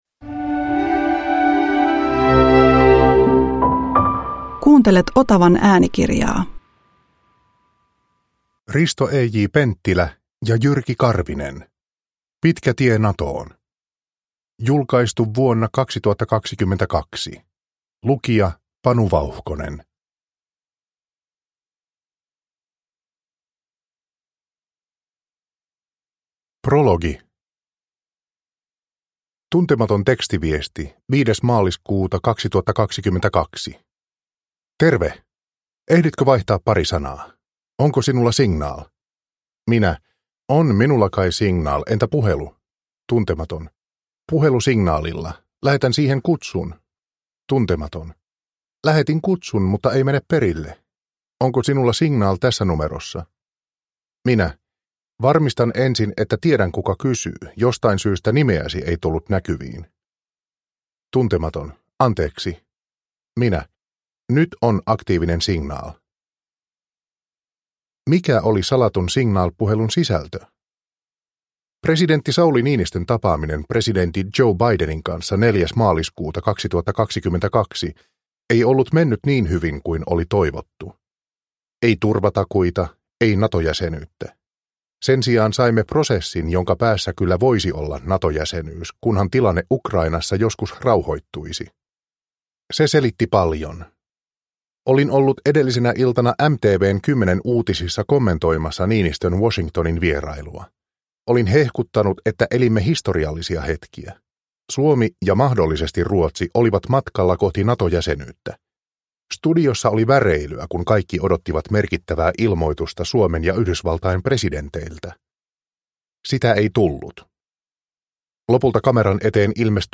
Pitkä tie Natoon – Ljudbok – Laddas ner